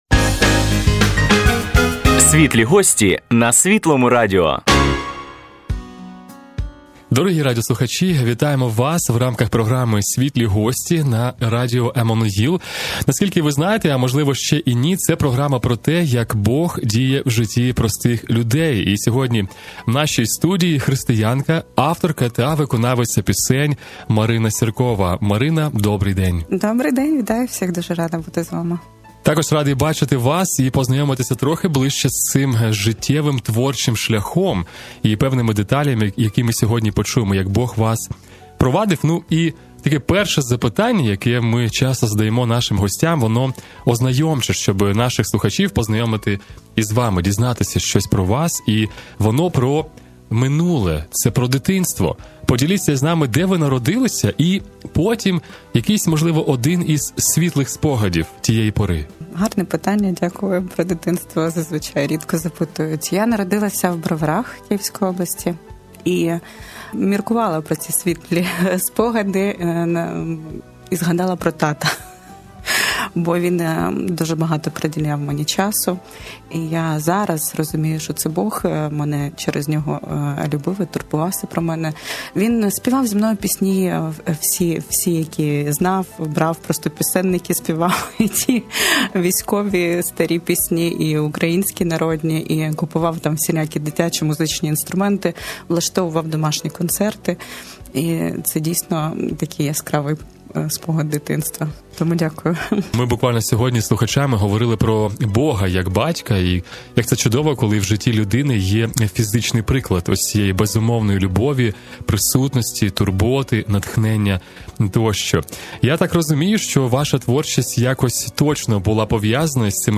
Під час інтерв'ю лунають три музичні композиції - "Тато", "В Тобі", "Хай буде воля Твоя".